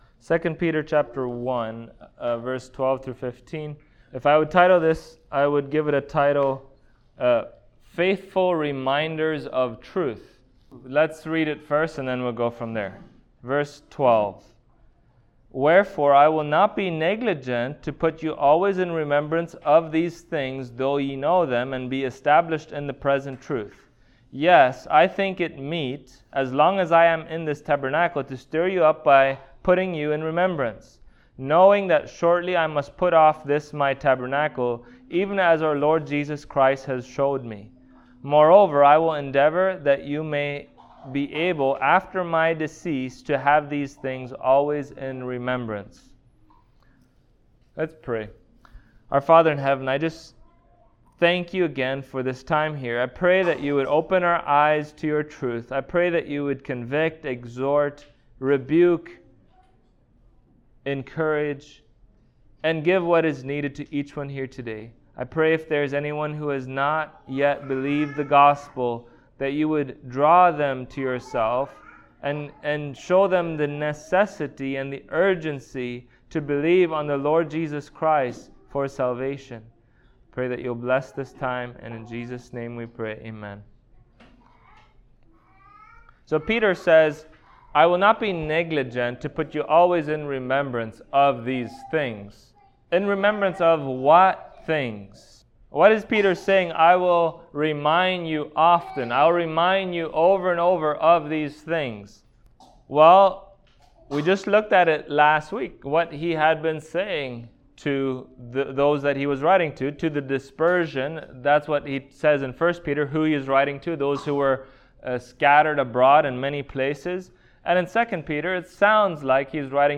2 Peter 1:12-15 Service Type: Sunday Morning Topics